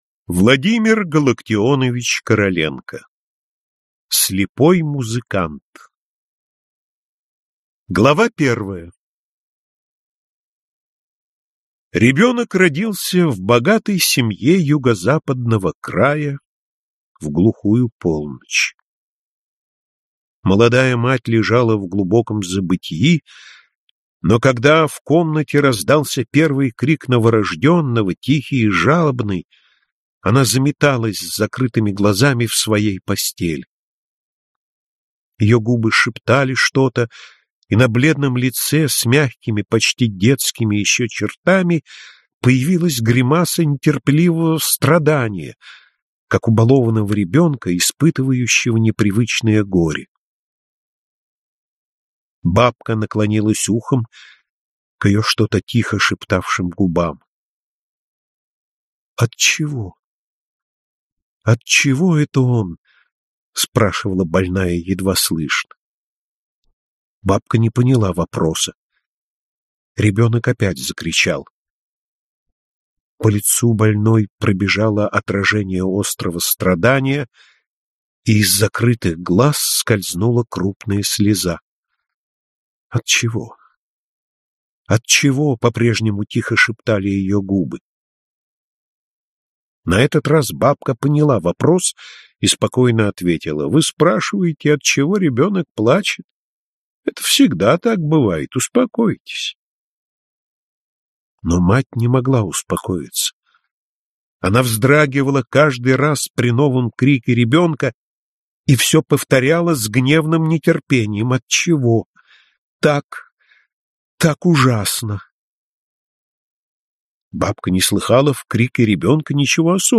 Аудиокнига Слепой музыкант | Библиотека аудиокниг